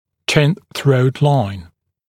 [ʧɪn θrəut laɪn][чин сроут лайн]линия перехода подбородка в шею